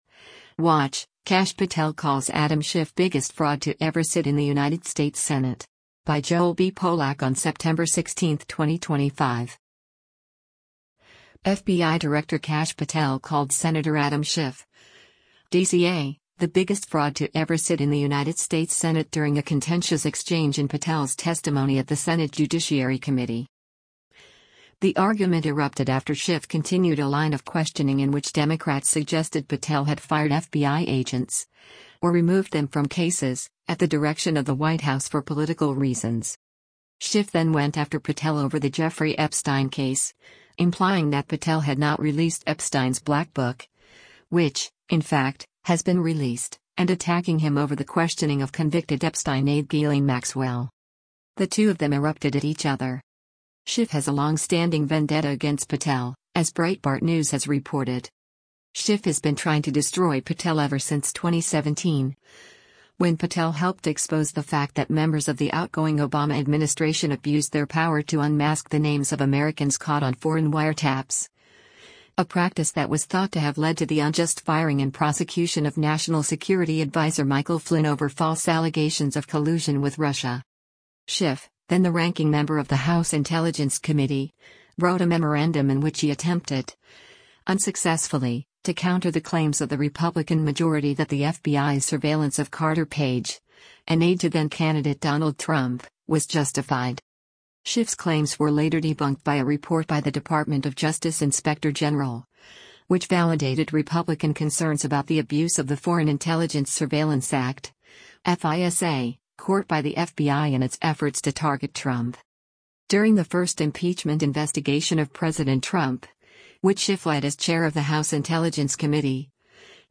FBI Director Kash Patel called Sen. Adam Schif (D-CA) “the biggest fraud to ever sit in the United States Senate” during a contentious exchange in Patel’s testimony at the Senate Judiciary Committee.
The two of them erupted at each other: